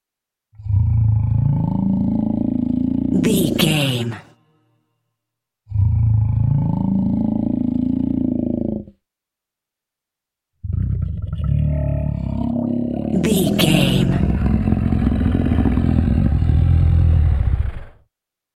Monster reptile snarl growl close x3
Sound Effects
ominous
eerie